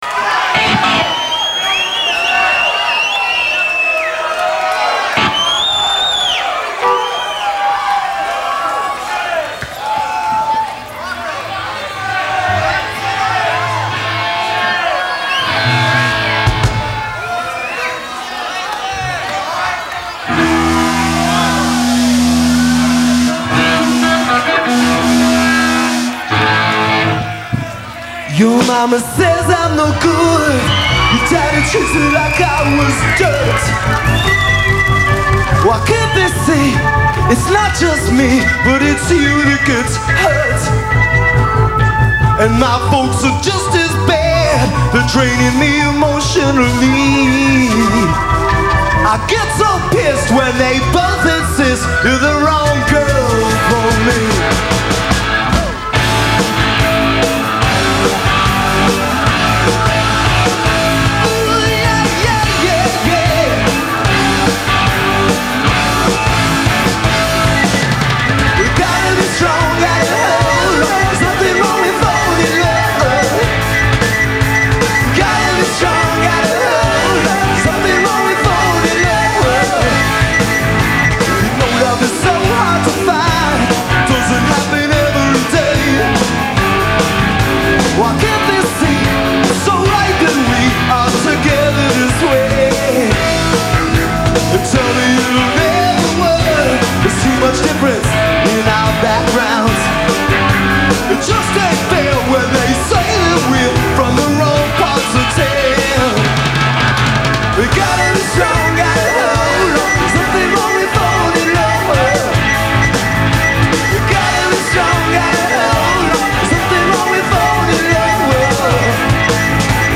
lead vocals
guitar
keyboards
bass